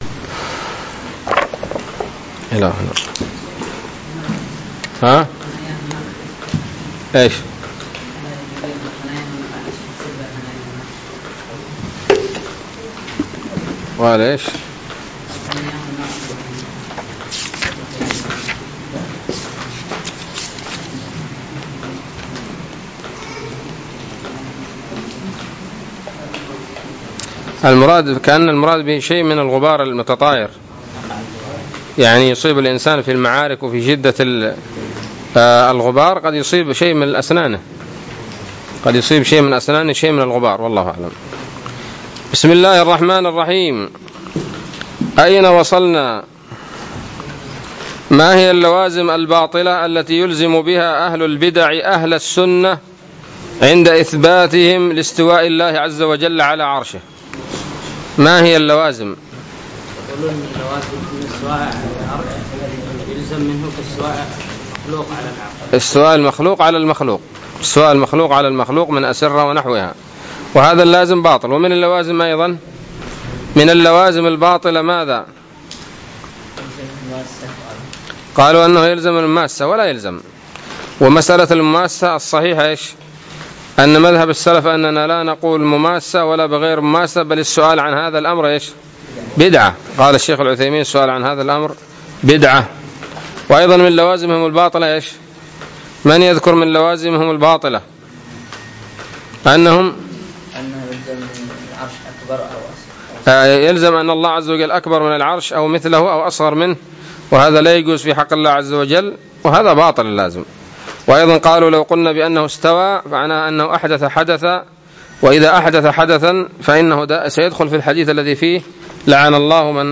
الدرس الرابع والسبعون من شرح العقيدة الواسطية